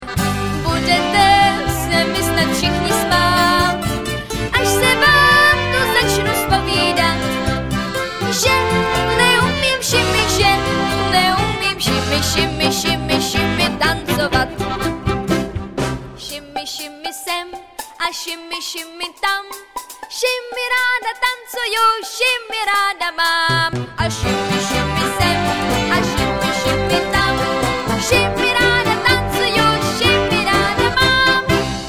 dechová sekce